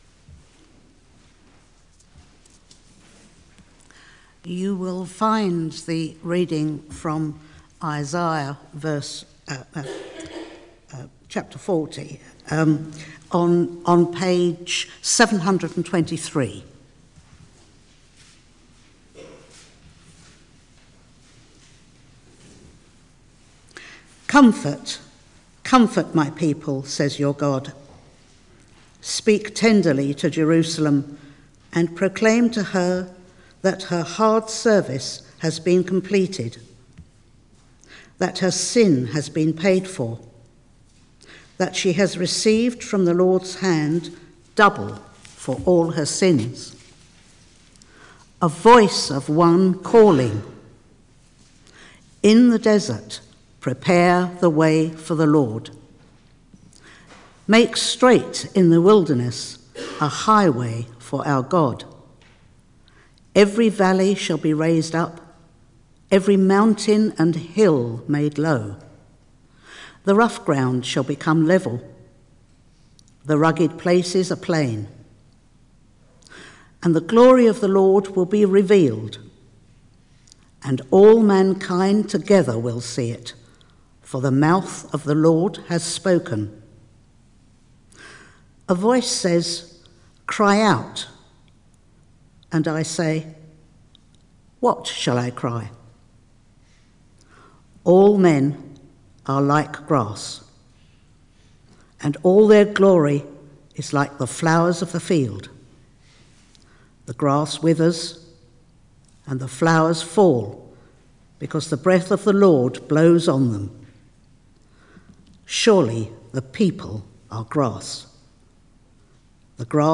Media for Arborfield Morning Service on Sun 22nd Dec 2013 10:00
Theme: Comfort in Christmas Sermon